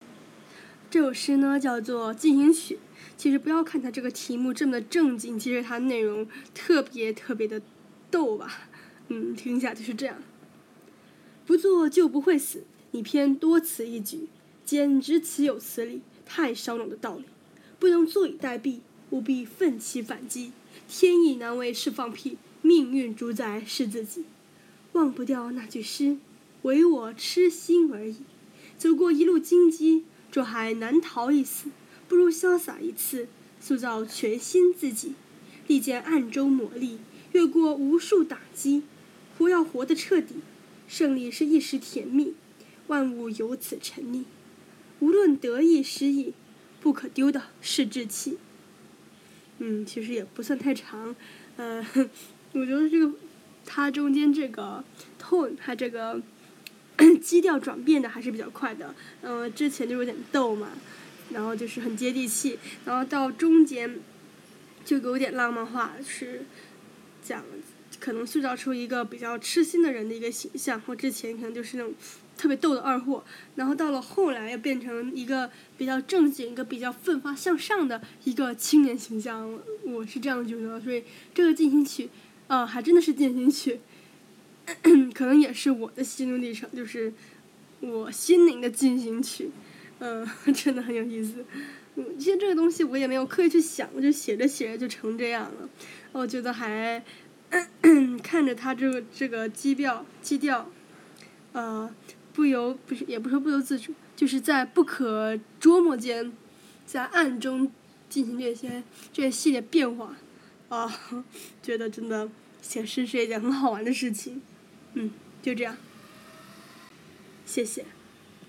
前期：逗逼二货中段：痴情少年尾声：老干体，绝对老干体！！